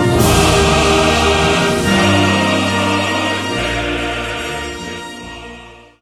warning_soft.wav